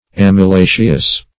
Amylaceous \Am`y*la"ceous\, a. [L. amylum starch, Gr.